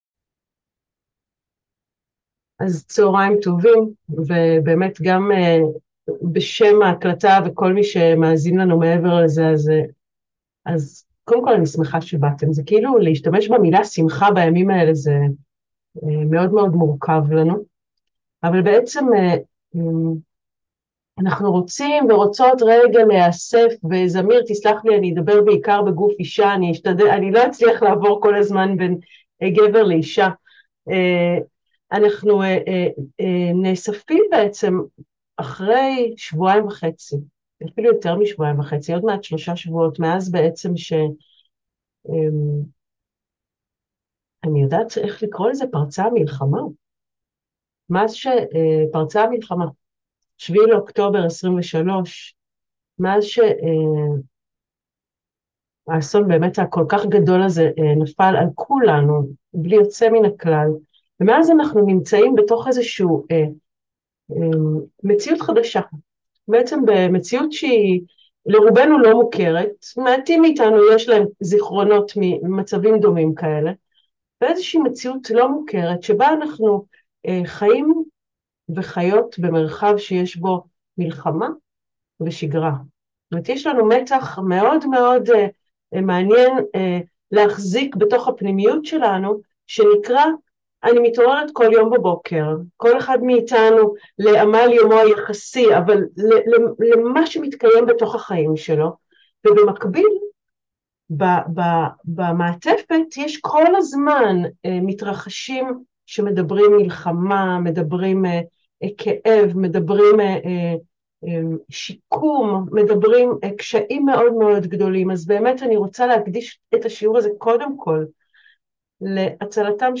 מהי קבלה? מה ההבדל בין קבלה לאהבה? ואיך הקבלה מסייעת לנו במצבים של מצוקה? שיעור מוקלט לימי מלחמה